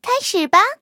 卡尔臼炮出击语音.OGG